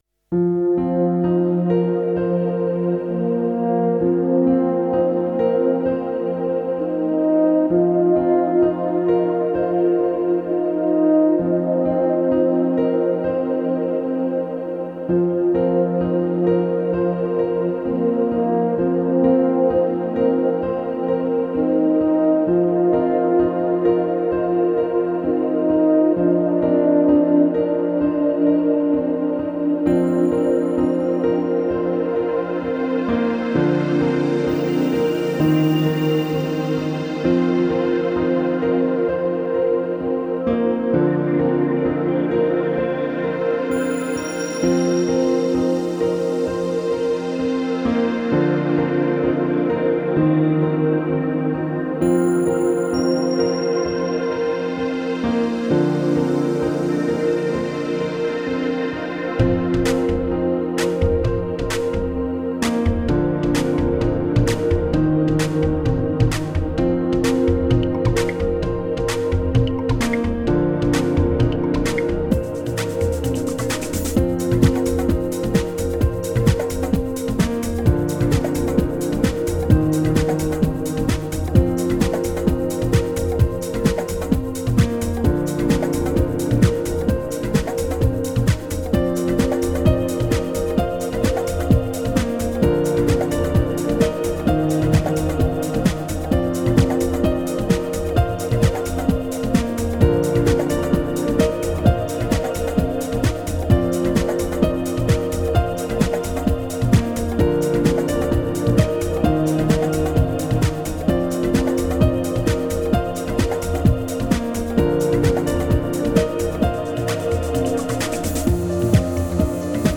Genre: Downtempo, New Age, Ambient.